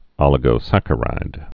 (ŏlĭ-gō-săkə-rīd, ōlĭ-)